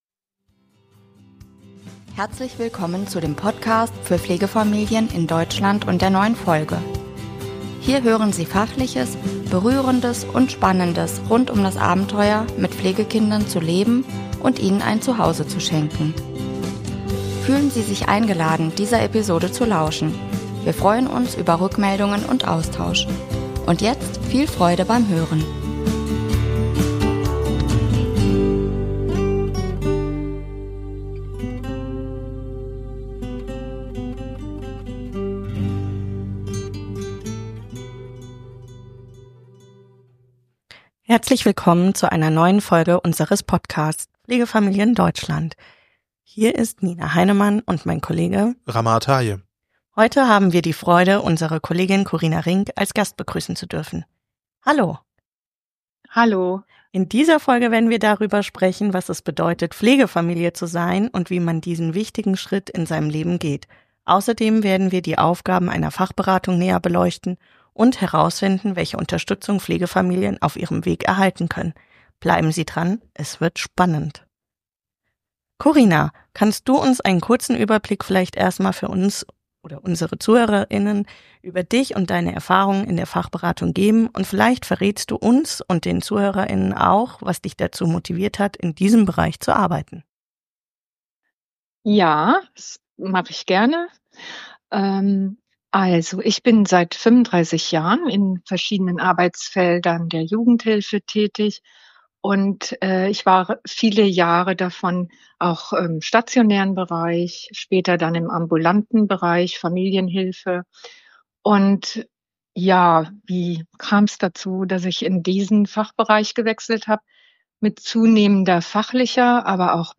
Seit April 2024 sind wir gemeinsame Hosts dieses Podcasts.